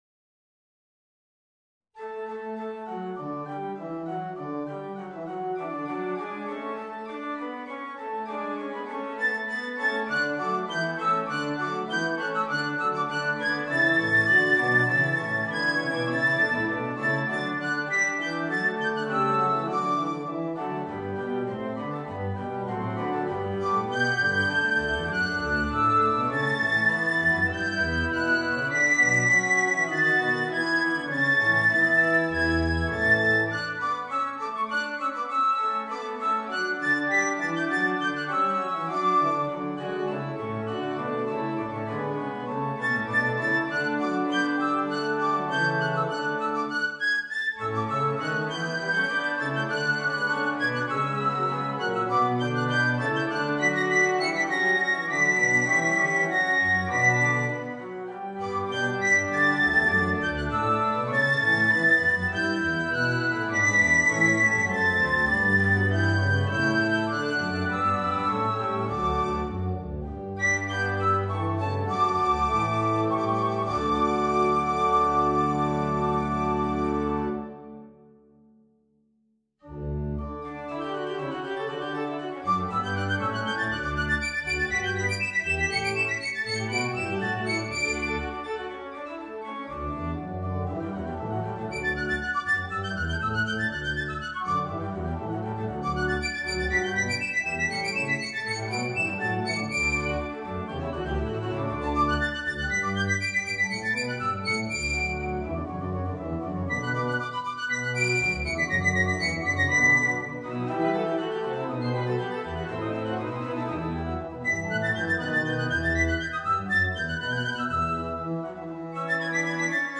Voicing: Piccolo and Organ